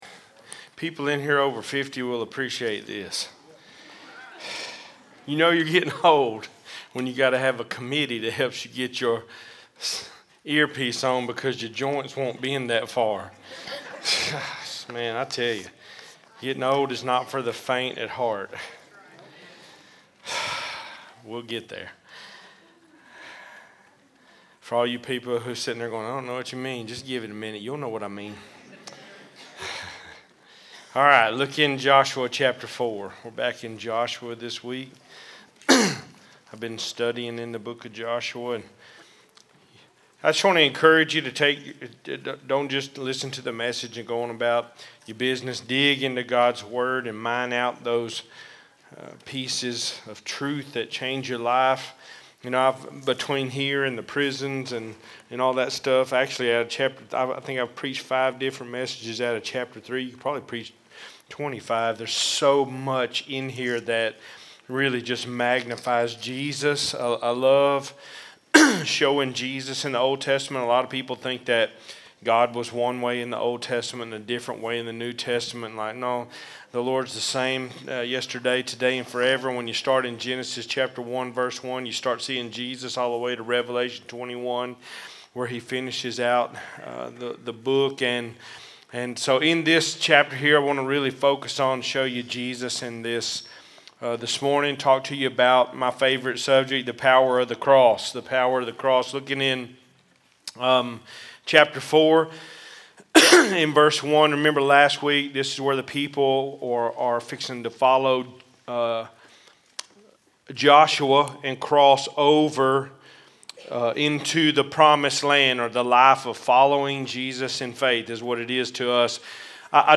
Cedar Creek Missionary Baptist Church Sermons